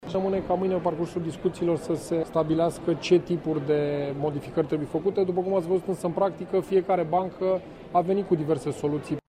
Secretarul de stat la Finanţe, Dan Manolescu, a declarat că se caută o soluţie pentru extinderea la această categorie de persoane a ordonanţei 46 care prevede posibilitatea deducerilor fiscale pentru restructurarea creditelor în cazul salariaţilor şi al pensionarilor cu venituri sub 2.200 de lei brut.